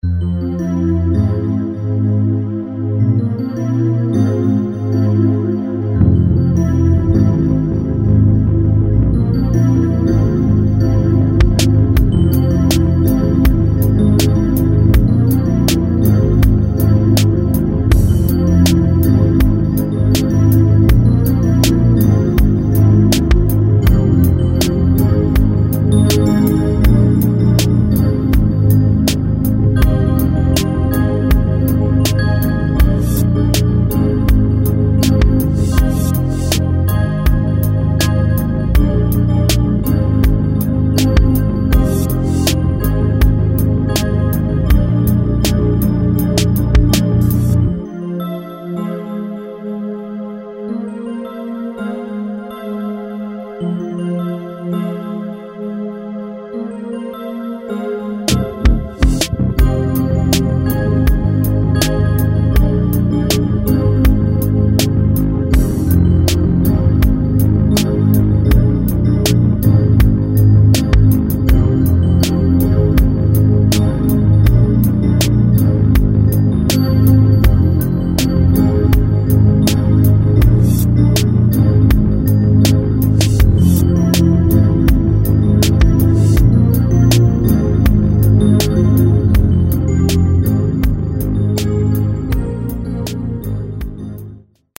instrumental piece